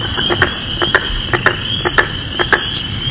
دانلود آهنگ قورباغه و جیرجیرک از افکت صوتی انسان و موجودات زنده
دانلود صدای قورباغه و جیرجیرک از ساعد نیوز با لینک مستقیم و کیفیت بالا
جلوه های صوتی